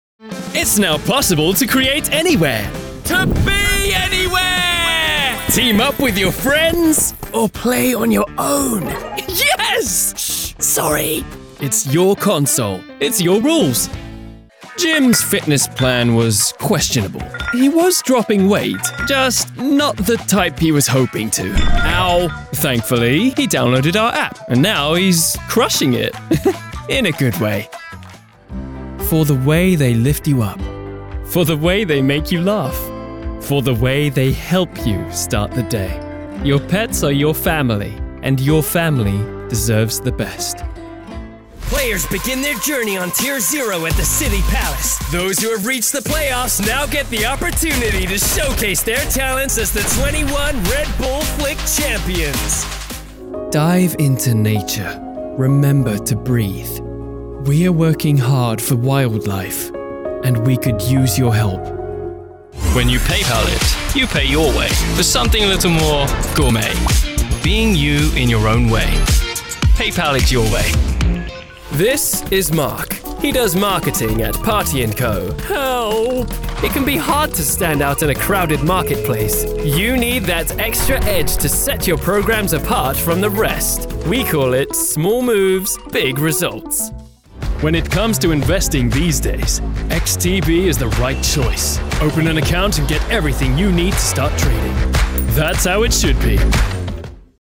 Comforting Voice Actors
English (British)
Friendly
Believable
Confident